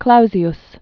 (klouzē-s), Rudolf 1822-1888.